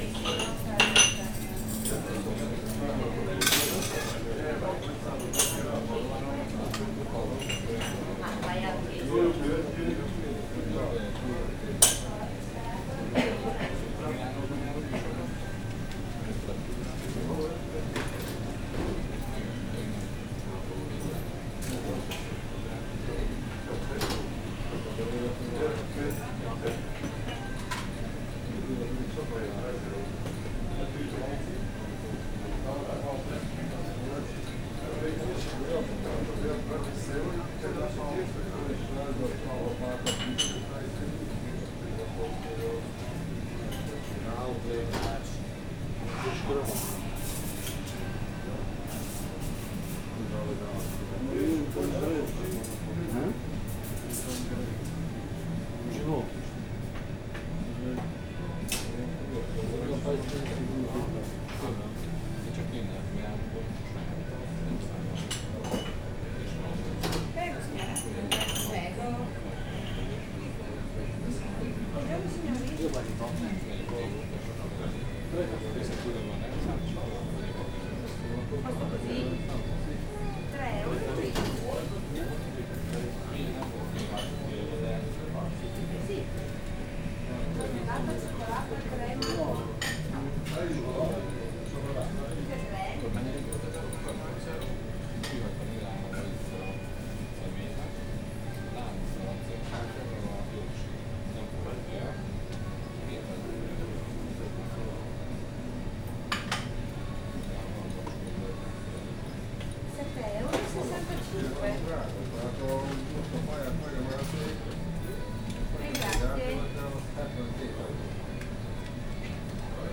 Directory Listing of /_MP3/hanggyujtemeny/olaszorszag_velence2014/vendeglo1/
kavescseszekzorgese_sds03.20.WAV